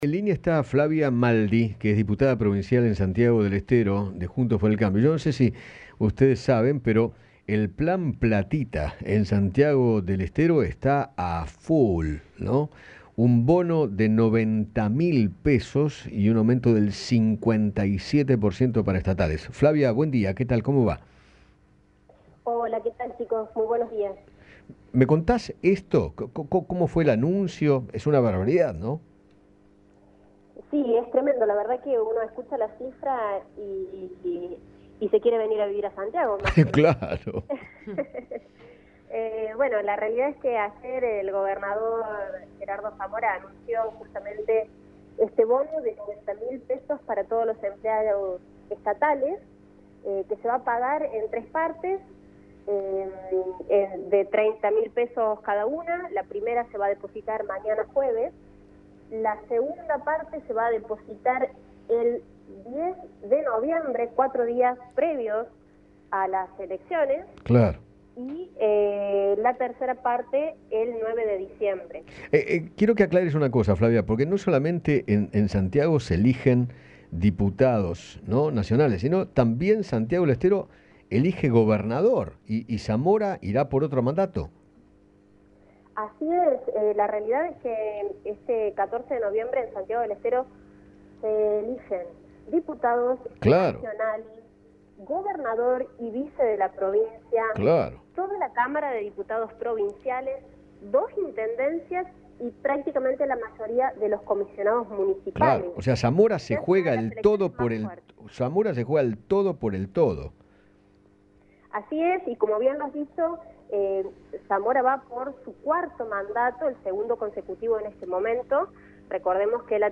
Flavia Maldi, diputada provincial, conversó con Eduardo Feinmann sobre el bono que anunció el gobernador santiagueño, Gerardo Zamora, para todos los empleados estatales, a un mes de las elecciones.